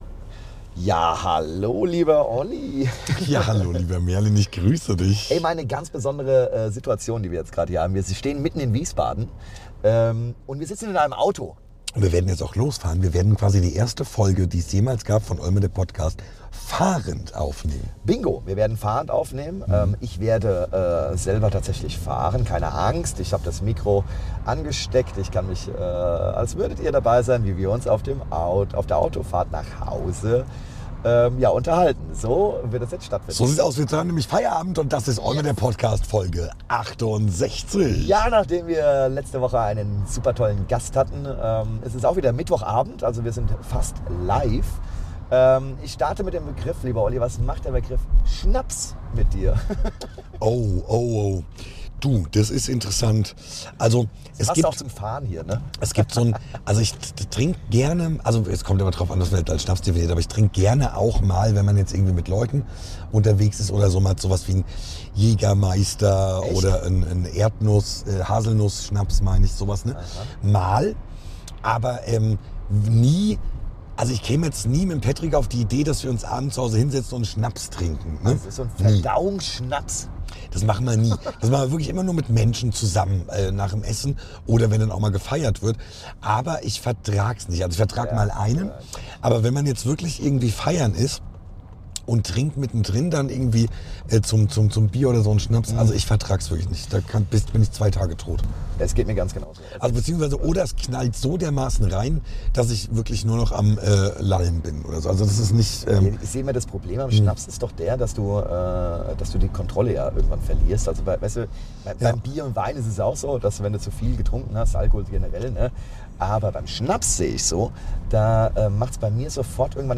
Beschreibung vor 4 Monaten Premiere bei OLME: Wir nehmen euch diesmal live aus dem Stau mit.